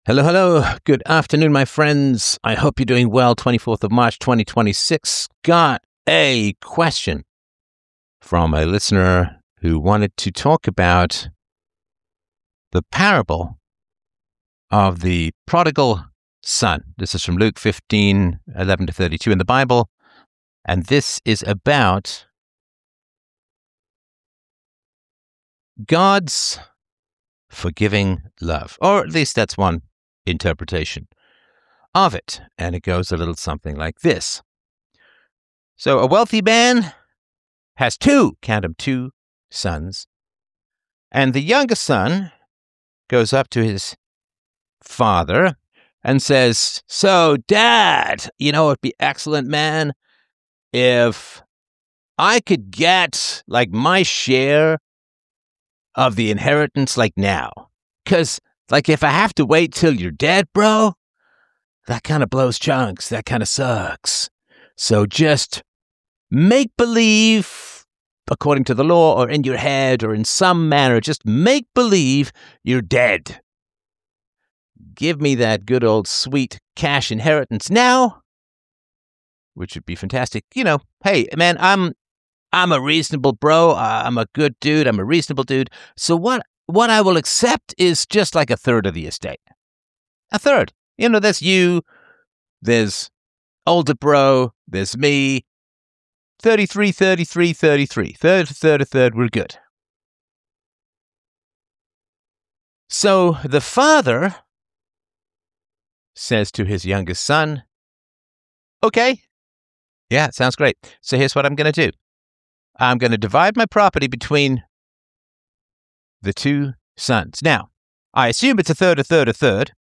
6347 The Ethics of the Prodigal Son! X Space
Philosopher Stefan Molyneux's 24 March 2026 Flash Livestream breaks down the Prodigal Son's fake forgiveness without remorse, father's enabling folly and welfare parallels to expose family corruption…